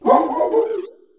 greavard_ambient.ogg